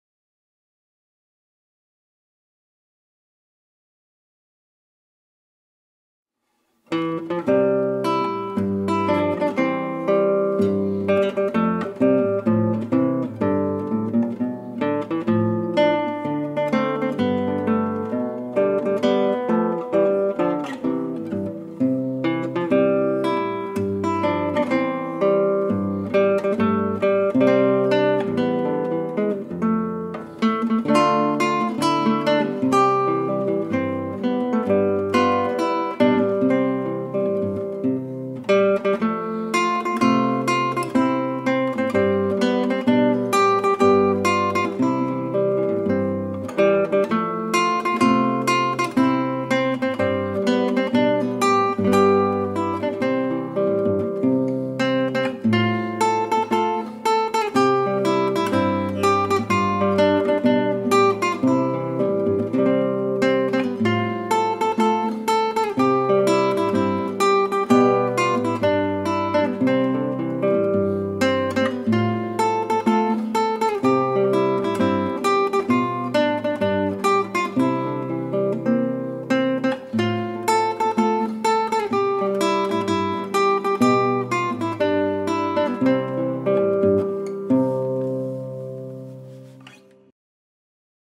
Гимн под гитару